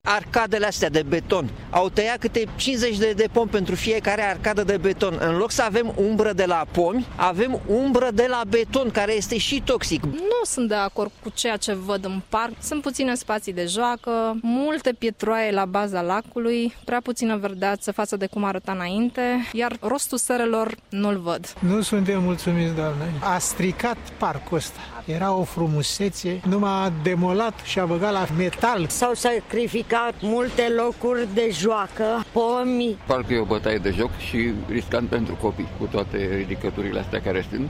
Oamenii spun că parcul a devenit de nerecunoscut, suprafaţa ocupată de spaţiul verde s-a restrans, iar în locul pomilor şi al plantelor au apărut poduri şi alei betonate: